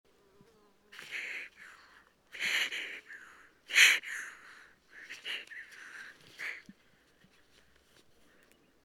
Horsefield’s Tortoise
from a Russian Tortoise Testudo horsfieldii uttering a threatening call.
Obviously my mammalian raptor like wind shield upsets him/her.
PFR05180, 110406, Russian Tortoise Testudo horsfieldii, threatening call, south Kazakhstan